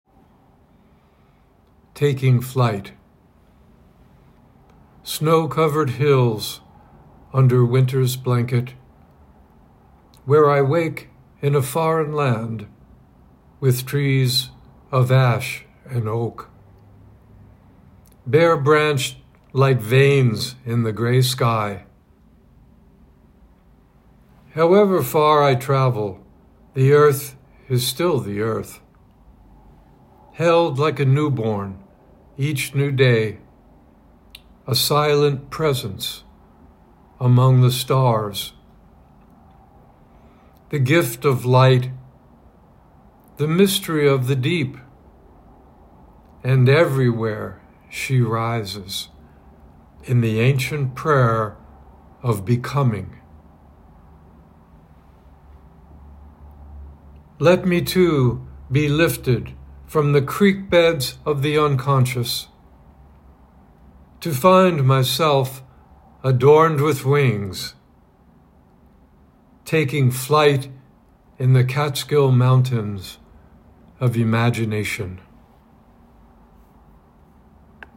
Reading of “Taking Flight”